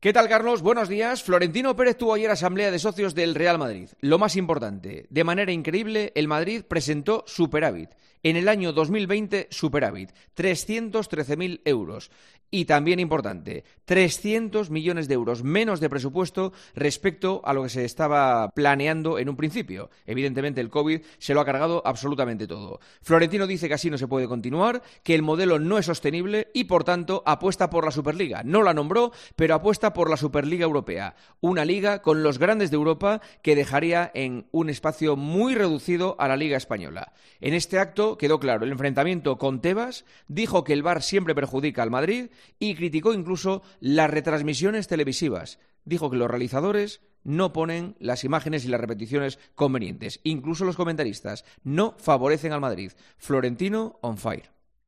El comentario de Juanma Castaño
Juanma Castaño analiza la actualidad deportiva en 'Herrera en COPE'